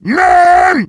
heavy_medic01.mp3